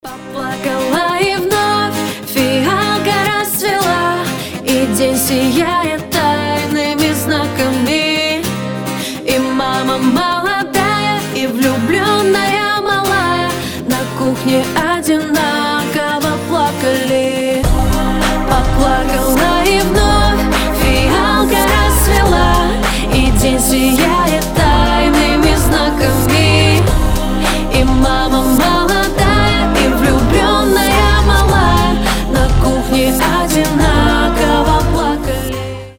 • Качество: 320, Stereo
поп
гитара
Cover